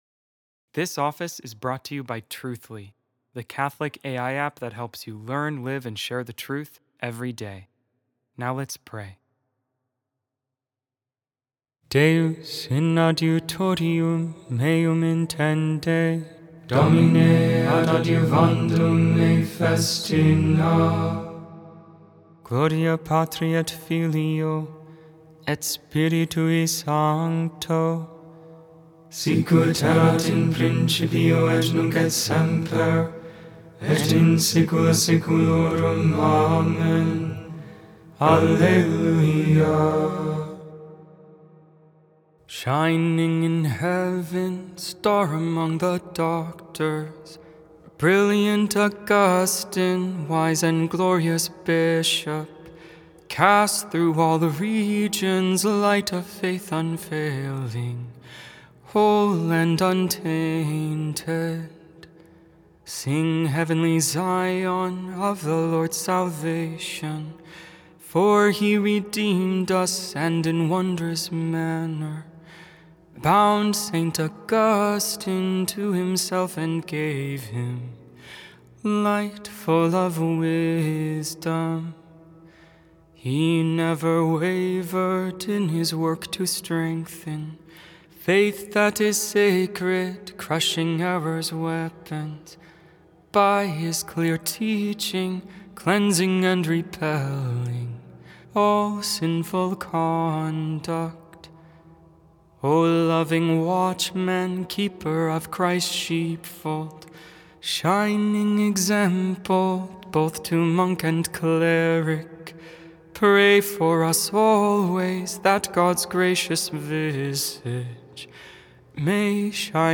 The Liturgy of the Hours: Sing the Hours